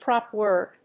アクセントpróp wòrd